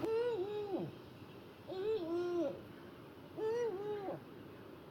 eagle2.ogg